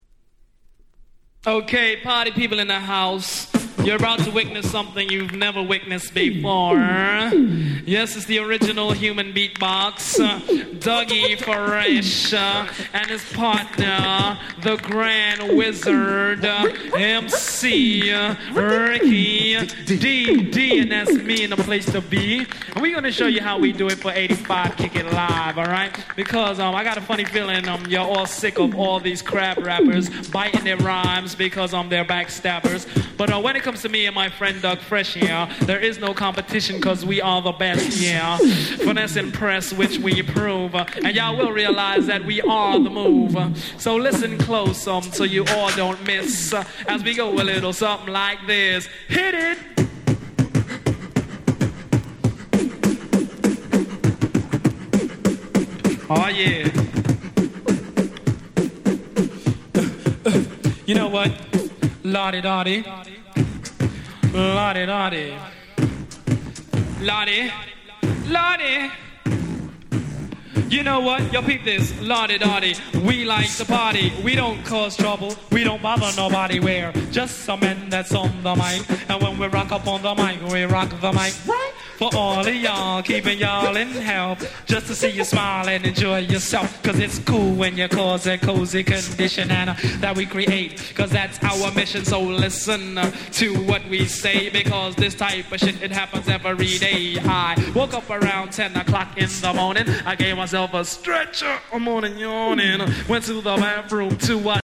85' Super Hip Hop Classics !!
問答無用のOld School HitにしてHip Hop古典。
ダギーフレッシュ スリックリック 80's オールドスクール Boom Bap ブーンバップ